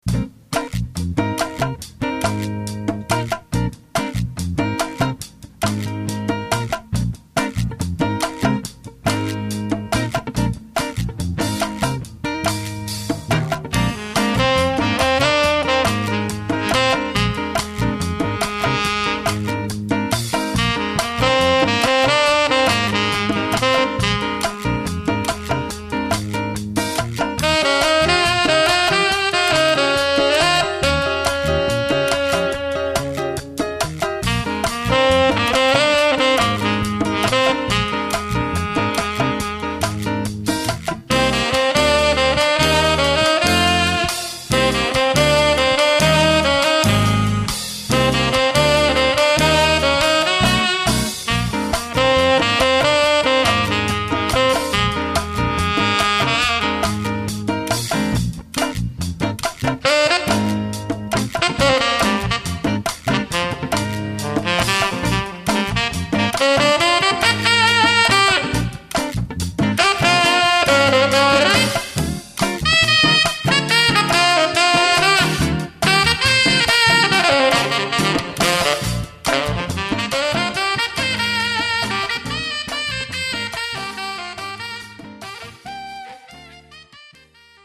jazz pop